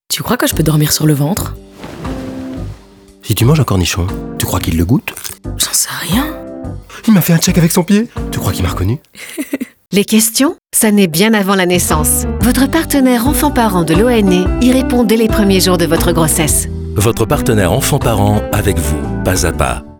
DES PUBS AUDIOS
Plus qu’un spot avec une bande sonore ordinaire, nous créons un univers sonore unique et distinct pour chaque spot avec des effets, des bruitages immersifs et une musique adaptée à votre production.